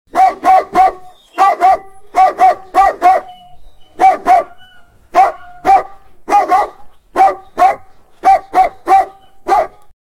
Angry Pitbull Dog Barking – Aggressive Guard Dog Téléchargement d'Effet Sonore